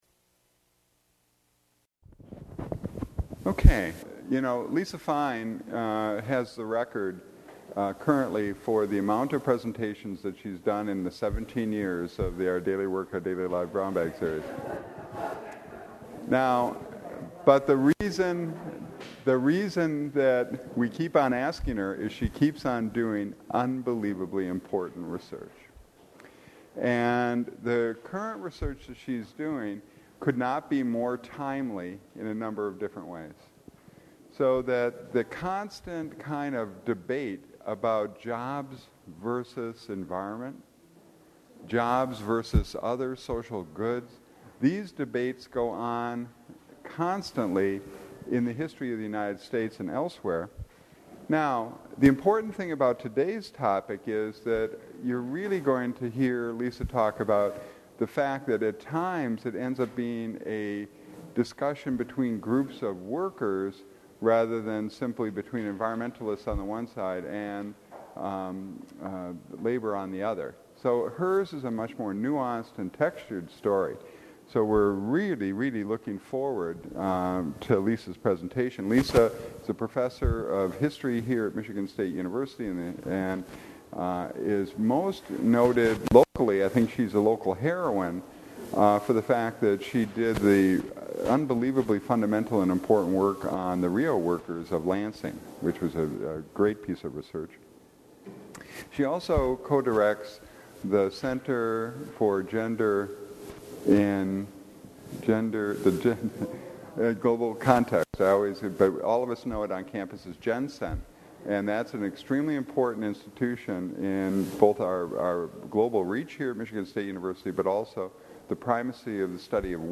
A question and answer session follows.
Held at the MSU Museum.